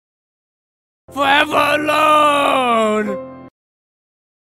Tags: meme troll airhorn